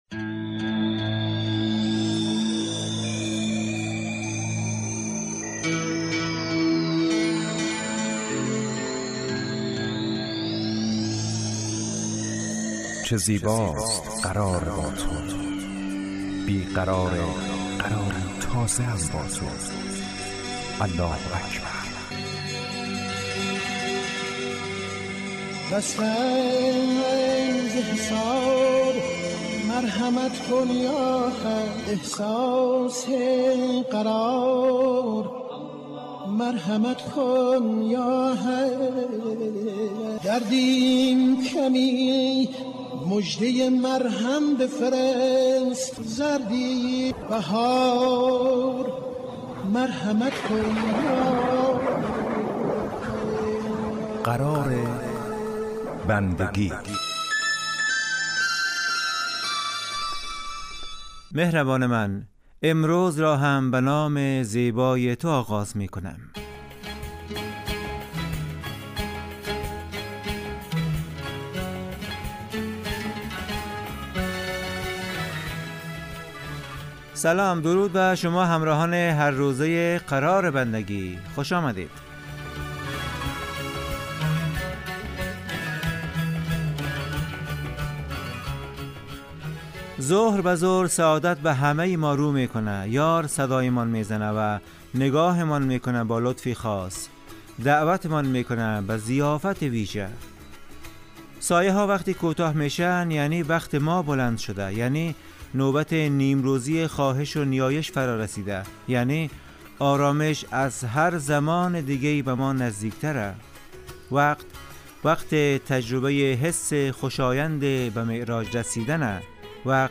قرار بندگی برنامه اذانگاهی در 30 دقیقه هر روز ظهر پخش می شود.